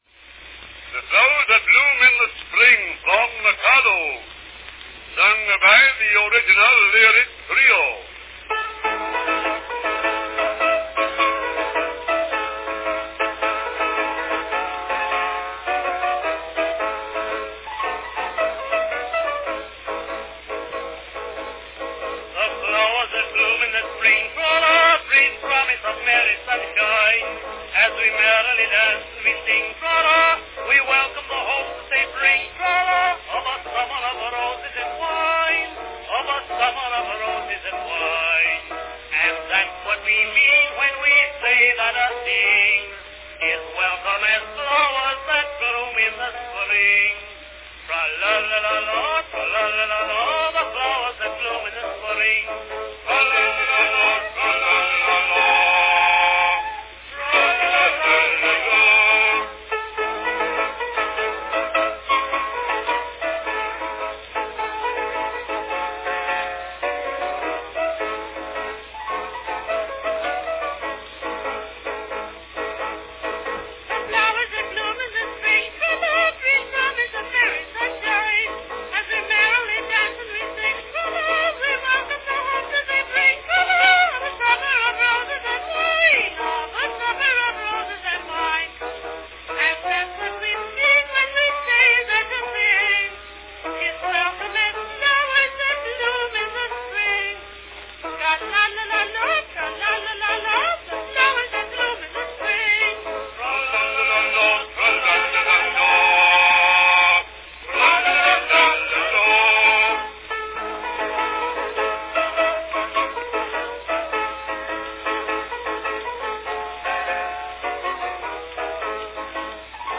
From 1898, the Original Lyric Trio perform "The Flowers that Bloom in the Spring" from The Mikado.
Category Trio
Performed by Original Lyric Trio
Announcement "The Flowers that Bloom in the Spring, from Mikado, sung by the Original Lyric Trio."
Enjoy this fine recording of the whimsical "The Flowers that Bloom in the Spring" from Gilbert & Sullivan's 1885 two act comic opera The Mikado.
Vocal trio recordings made relatively infrequent appearances in Edison's early two-minute wax cylinder record catalogs – approximately a dozen or so compared with the hundreds of duet and quartette selections available.